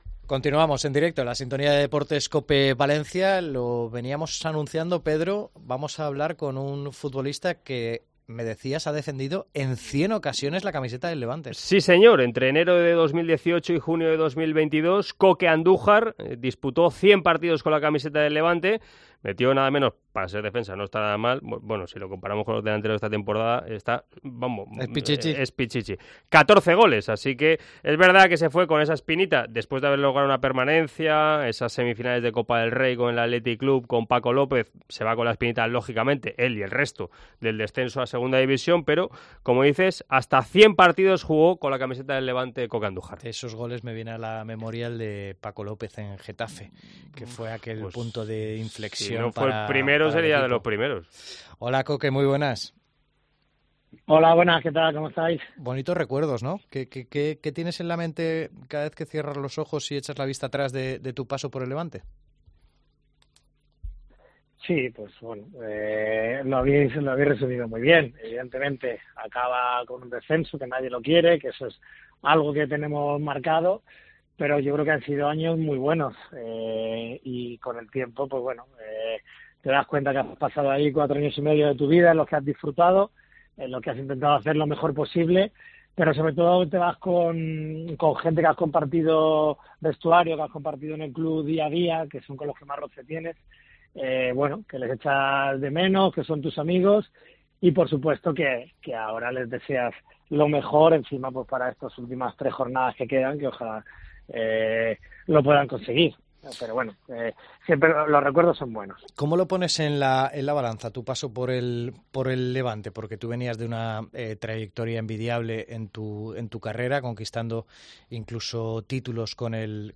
Entrevista en COPE a Coke, exjugador del Levante UD